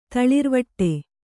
♪ taḷirvaṭṭe